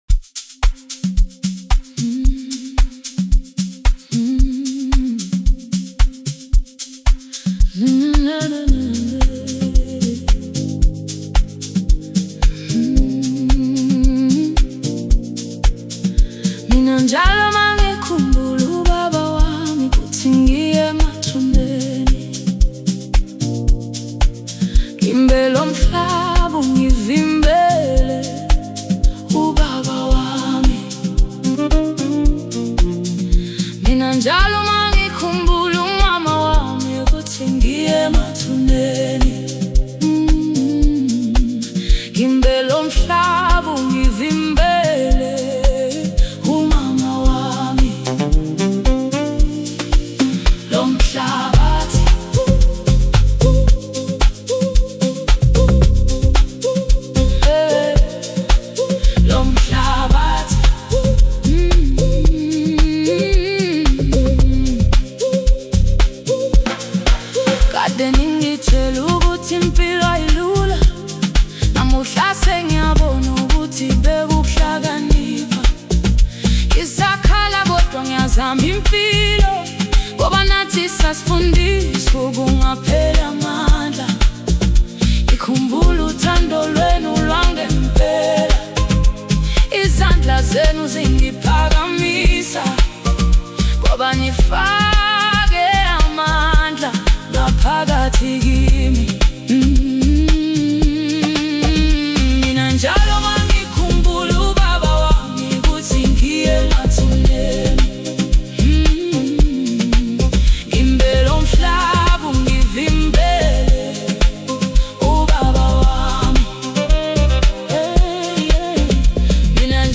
Home » Amapiano » DJ Mix » Hip Hop
captivating and harmonious tune